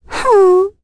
Ophelia-vox-Sad.wav